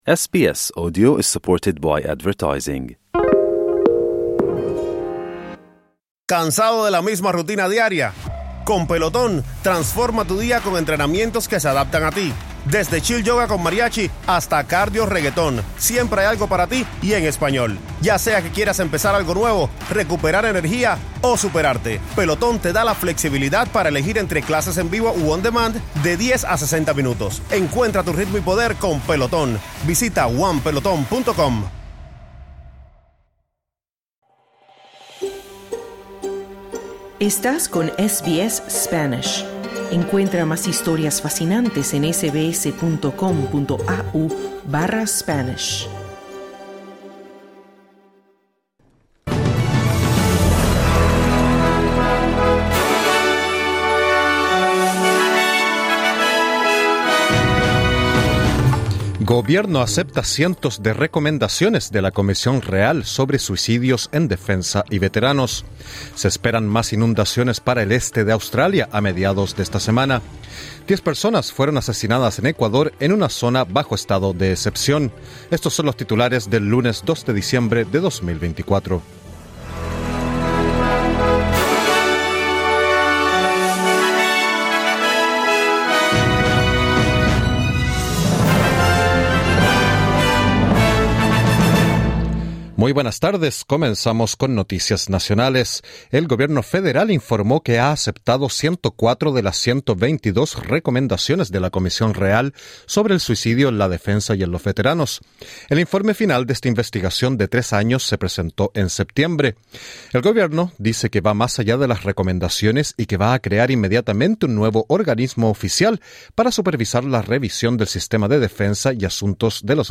Noticias SBS Spanish | 2 diciembre 2024